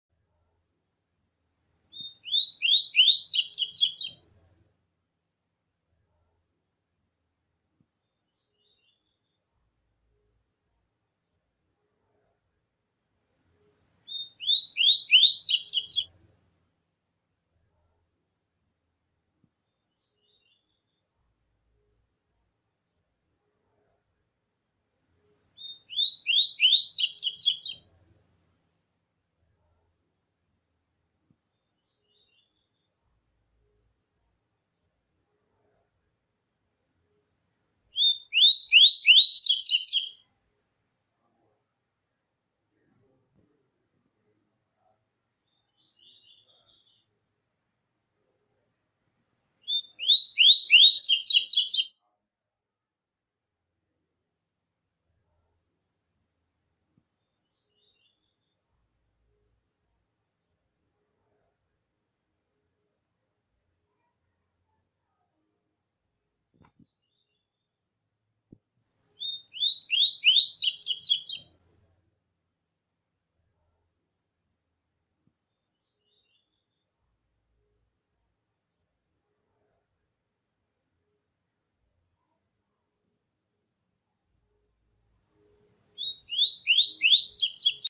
Tico Tico Tui Tui, Alguém Sound Effects Free Download
Upload By Canto de Pássaros